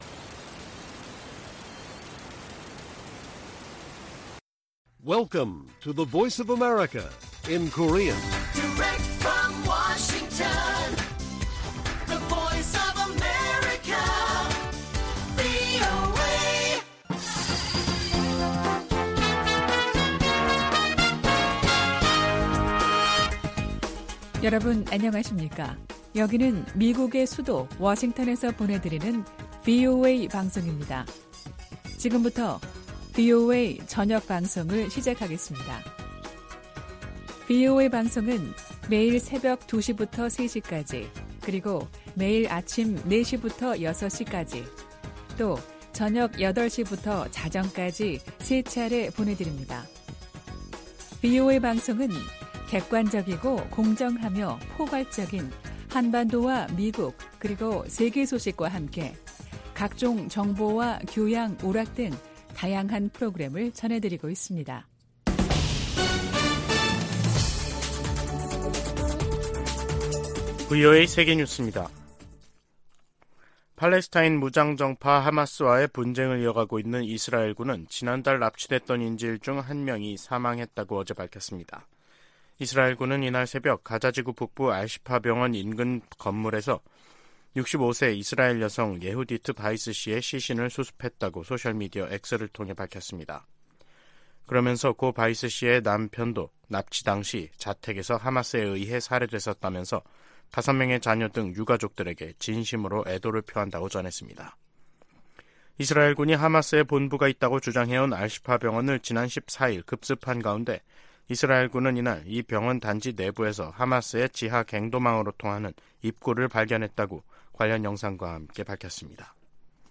VOA 한국어 간판 뉴스 프로그램 '뉴스 투데이', 2023년 11월 17일 1부 방송입니다. 조 바이든 미국 대통령과 기시다 후미오 일본 총리가 타이완해협, 한반도, 동중국해 등에서의 평화와 안정이 중요하다는 점을 재확인했습니다. 미국은 동맹국의 핵무기 추구를 단호히 반대해야 한다고 국무부의 안보 관련 자문위원회가 주장했습니다. 미중 정상이 양국 간 갈등을 완화하는 데 동의함으로써 한반도 안보 상황에도 긍정적으로 작용할 것이라는 관측이 나오고 있습니다.